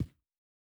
Shoe Step Stone Medium A.wav